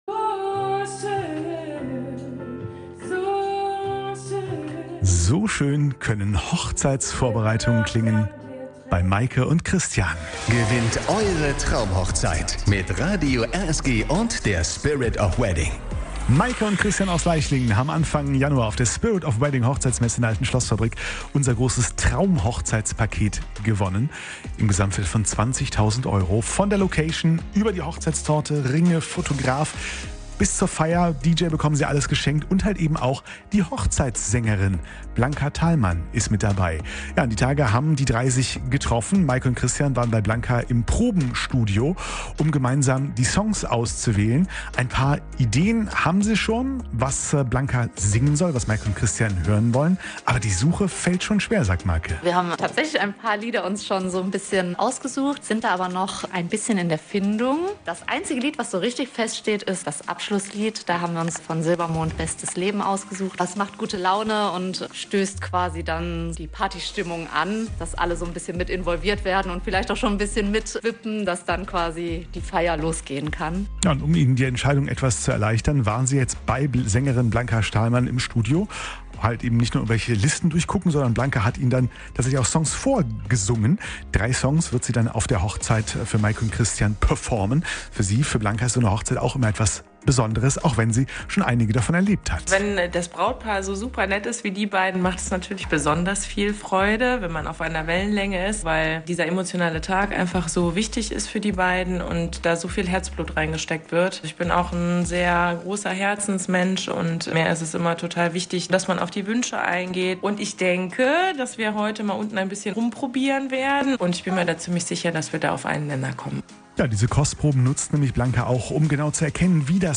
Hochzeitssängerin
zur Live-"Hörprobe" im Probenkeller